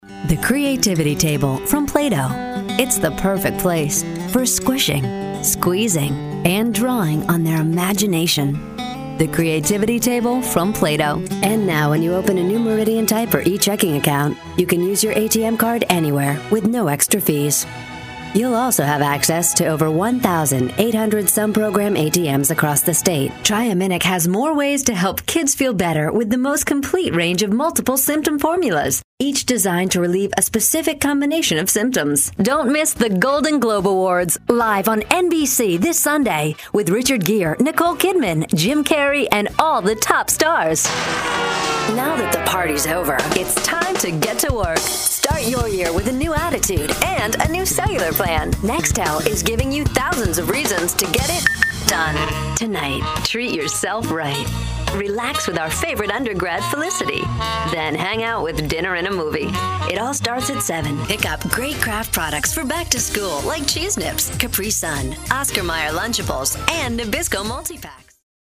Sexy, Smooth, Warm